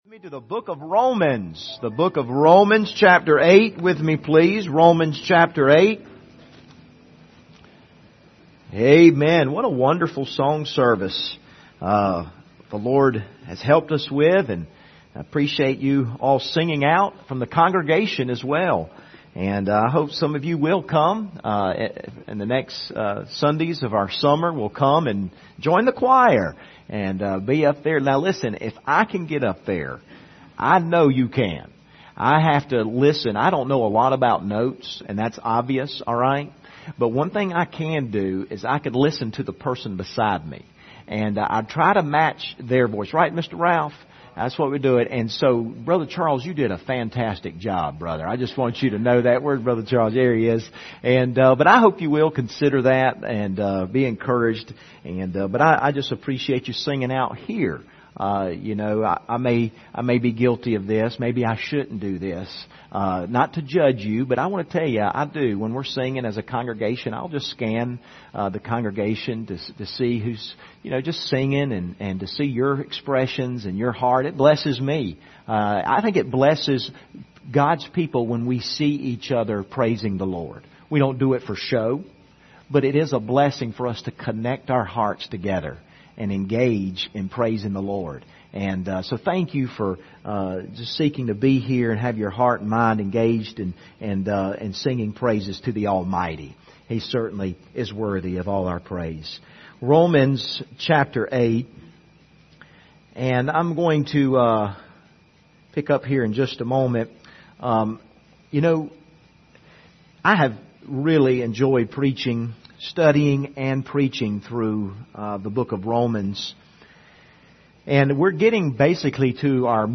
Passage: Romans 8:28-30 Service Type: Sunday Morning